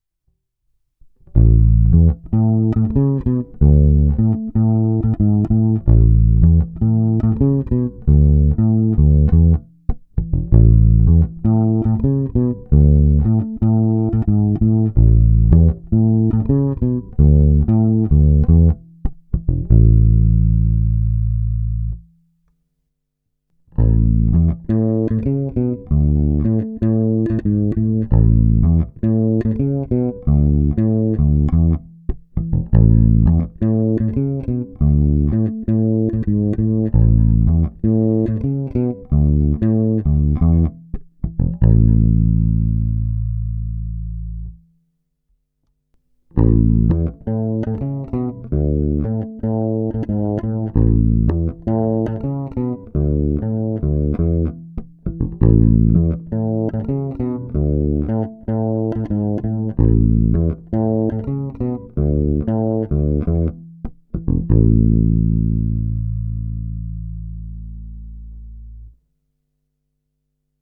Nahrál jsem několik ukázek v pořadí krkový snímač, oba snímače, kobylkový snímač. Použité struny jsou notně hrané nylonové tapewound hlazenky Fender 9120. Nahráno je to vždy přímo do zvukové karty a nahrávky byly jen normalizovány, jinak ponechány bez dodatečných úprav.
Pasívní režim se staženou tónovou clonou na cca 50%